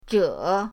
zhe3.mp3